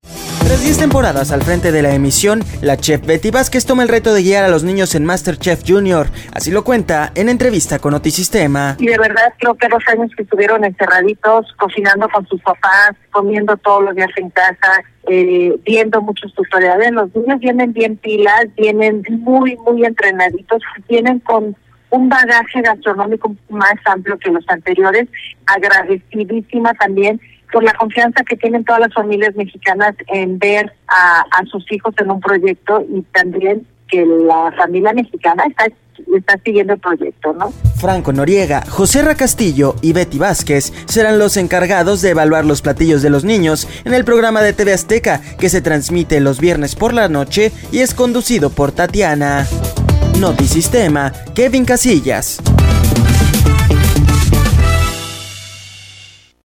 Tras 10 temporadas al frente de la emisión, la chef Betty Vázquez toma el reto de guiar a los niños en Masterchef Junior, así lo cuenta en entrevista con Notisistema.